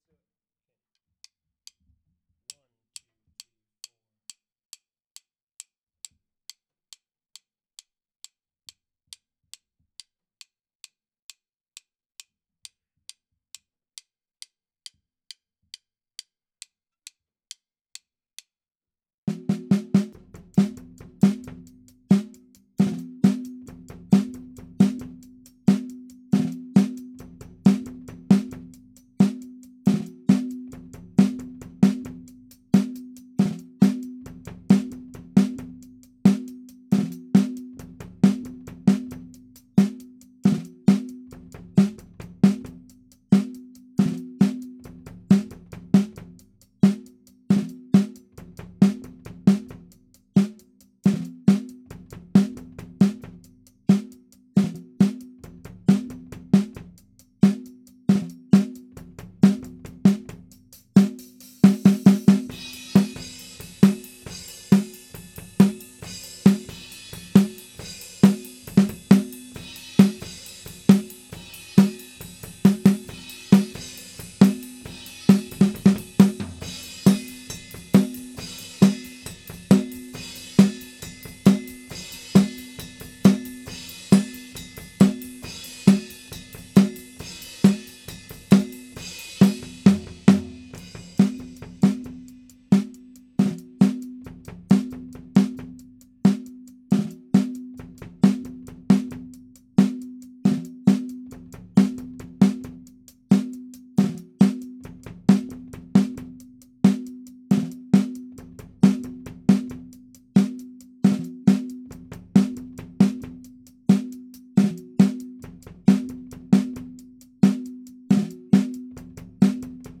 Snare 1 (2).wav